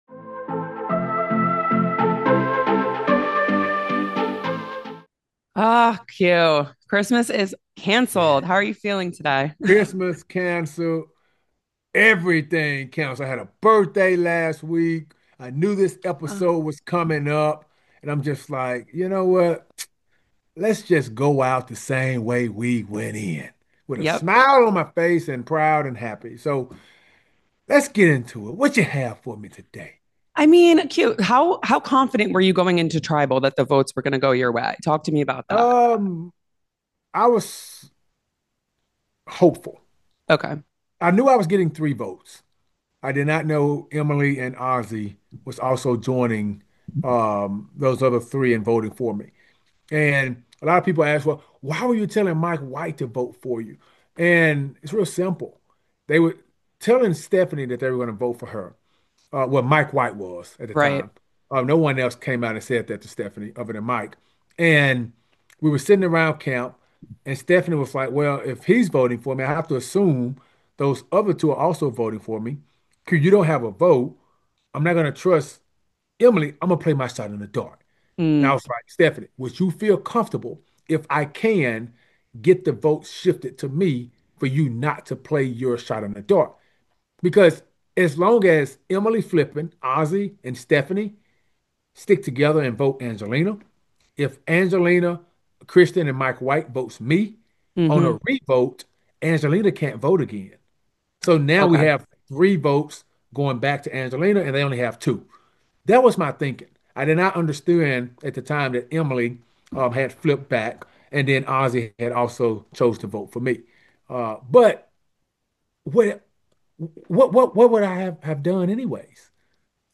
Survivor 50 Exit Interview